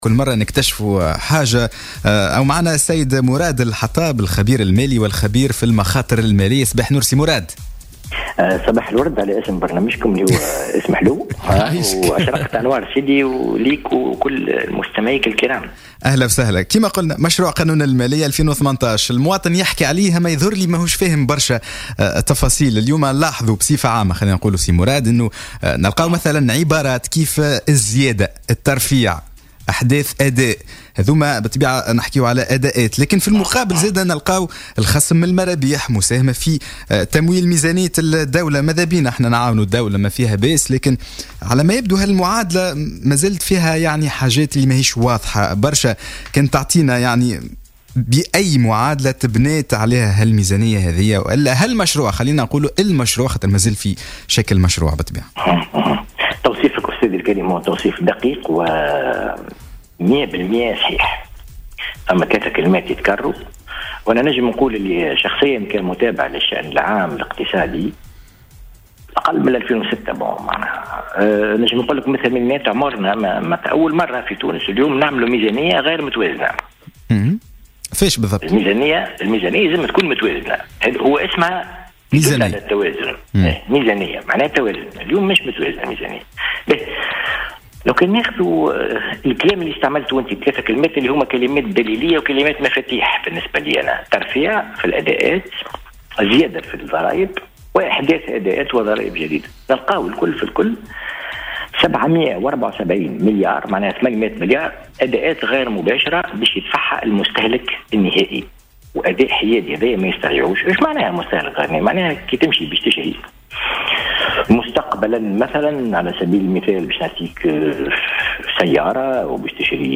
وجاء ذلك على هامش تعليقه على قانوني المالية وميزانية الدولة 2018 على "الجوهرة اف أم" ضمن برنامج "صباح الورد"، واصفا الميزانية المقترحة بـ "ميزانية غير متوازنة" وهي وضعية أولى من نوعها بحسب تعبيره.